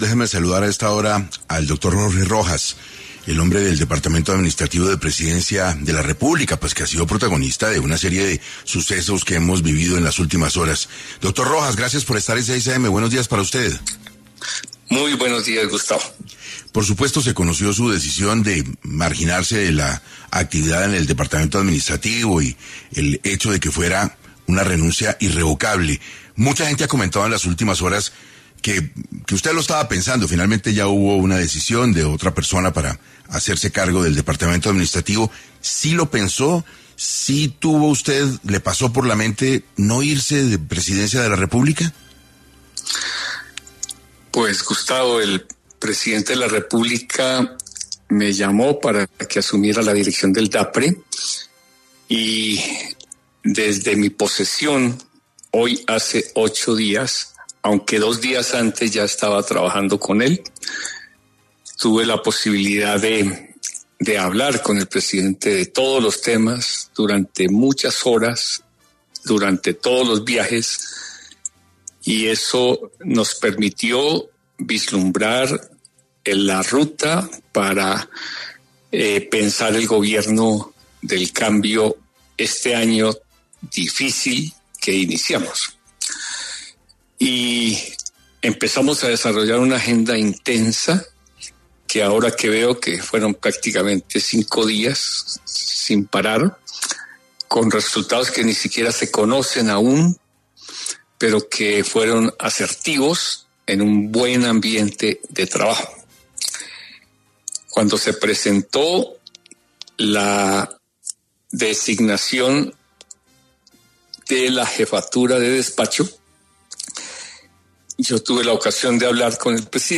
En Caracol Radio estuvo Jorge Rojas, exdirector del DAPRE, quien dio detalles de su renuncia “irrevocable”.